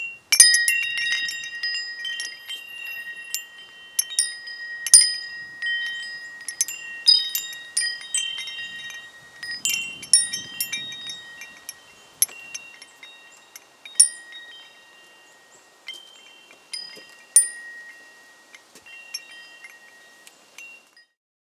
windchime1.R.wav